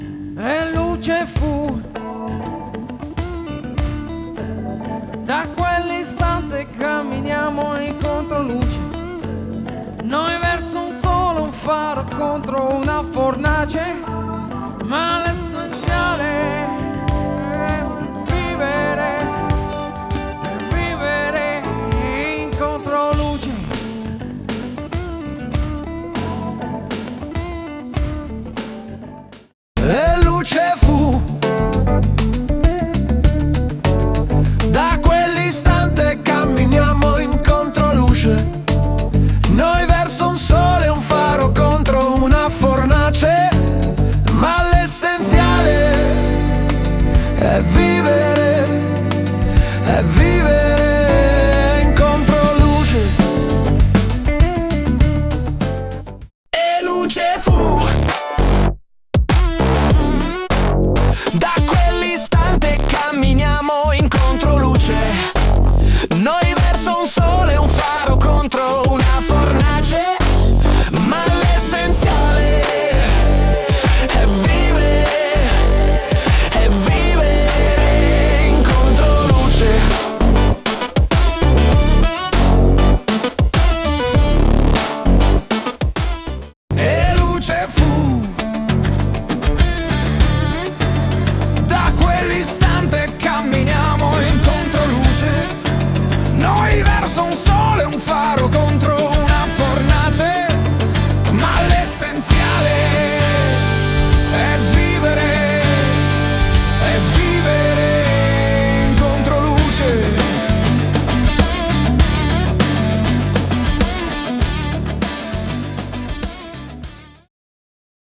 • 00:30: Indie / Neo Soul
• 00:56: Hyperpop
• 01:26: Hard Rock / Metal